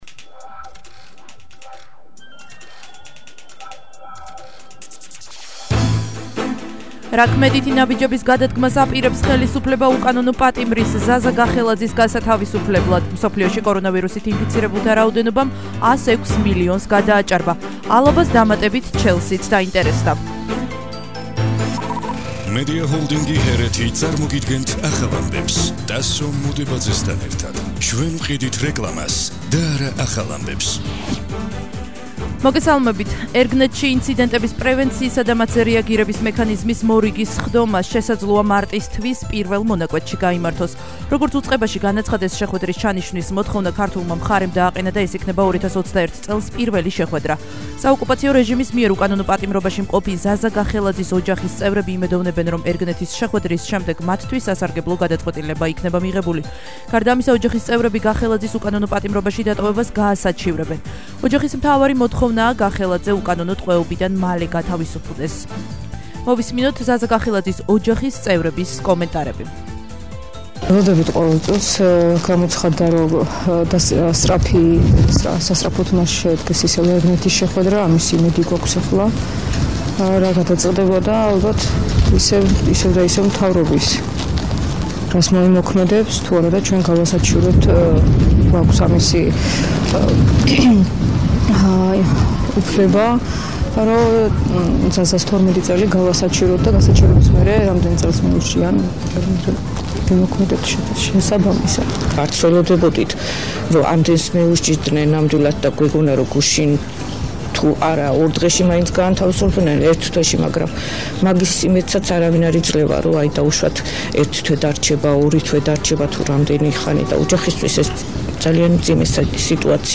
მთავარი საინფორმაციო გამოშვება –09/02/21 - HeretiFM